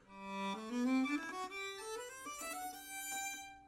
Der Vergleich einer normalen Geige mit Hoteldämpfer und einer Silent-Violine mag hinsichtlich der Lautstärkereduktion überraschen, denn hinsichtlich der Lautstärke sind beide Lösungen in etwa identisch, wie sich dies an den folgenden Klangbeispielen, die in einem Raum in Wohnzimmergröße mit demselben Mikrofon unter gleichen Bedingungen aufgenommen wurden, nachvollziehen lässt:
Normale Geige mit Hoteldämpfer (Metalldämpfer mit Gummiüberzug)
hoteldaempfer.ogg